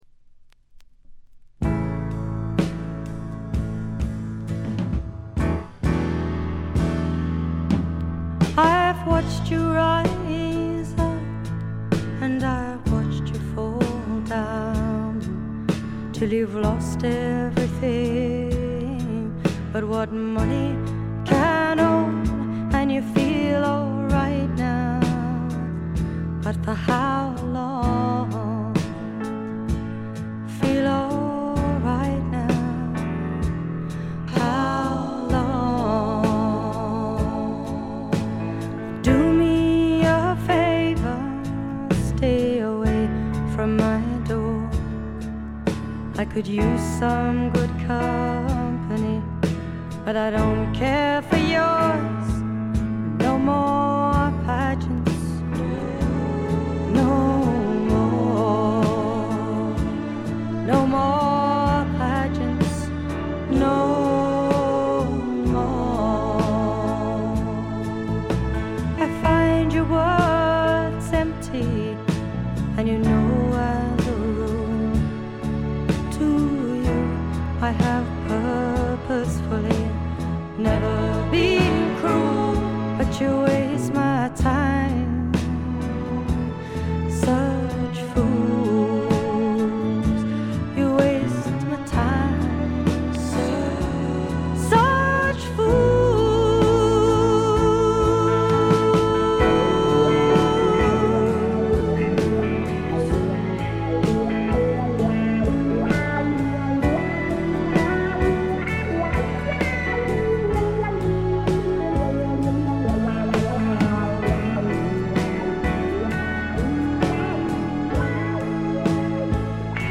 軽微なチリプチ程度。
1stのようないかにもな英国フォークらしさは影を潜め、オールドタイミーなアメリカンミュージック風味が加わってきています。
試聴曲は現品からの取り込み音源です。